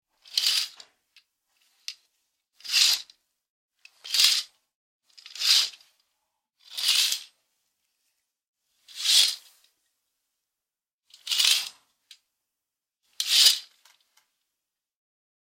MKuuwxeKayD_curtains-72068.mp3